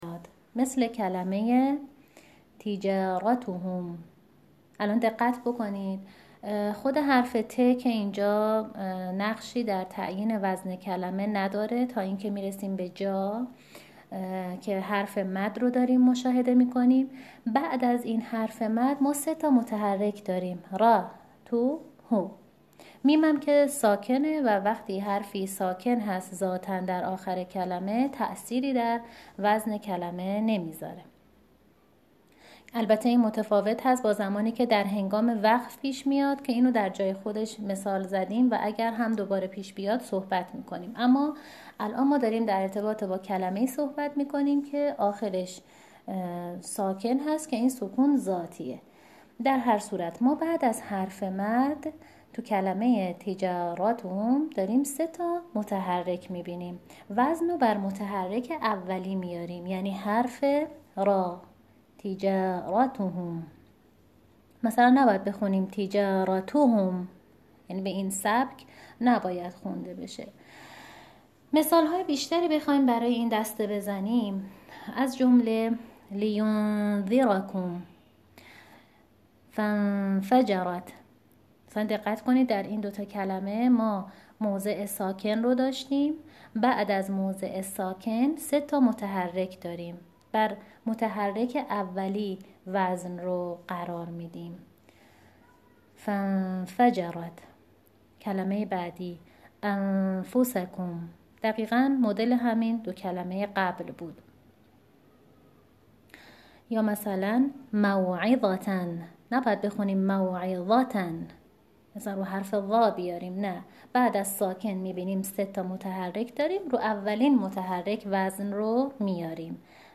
بخش اول، کلماتی هستند که بعد از حرف مد، ساکن و یا تشدید، سه متحرک داشته باشیم که در این صورت وزن بر متحرک اول قرار می‌گیرد چون تِجارَتَهُم(بعد از حرف مد، وزن کلمه بر حرف متحرک اولی یعنی حرف راء قرار می‌گیرد)، فَانفَجَرَت، أنفُسَکُم، مَوعِظَةٍ و...